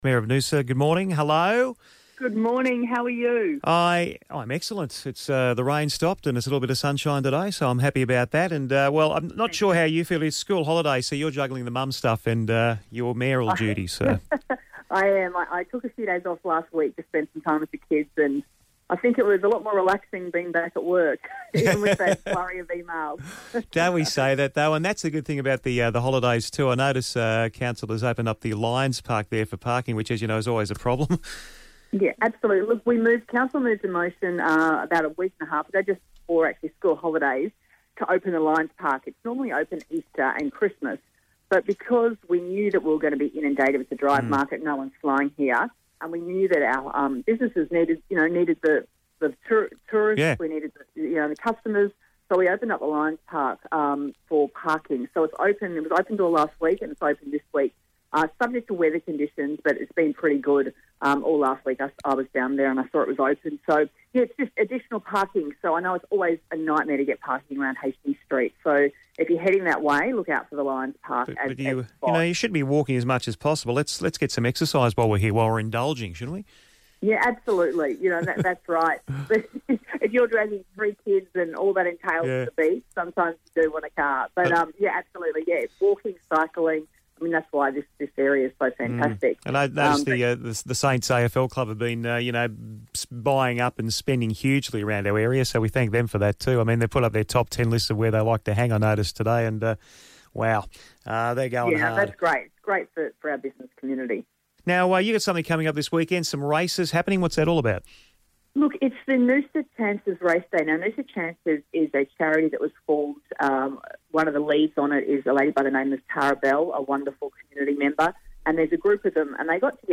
chats with Mayor of Noosa, Clare Stewart on where to find more parking these holidays.